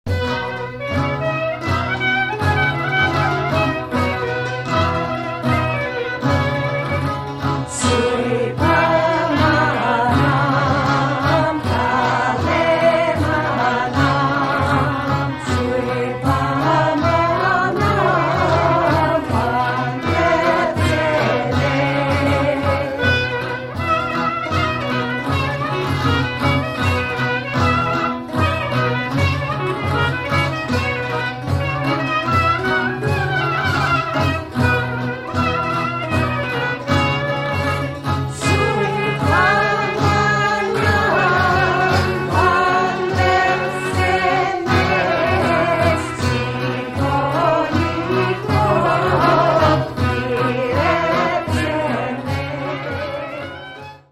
The song is in the ancient 5/4 meter.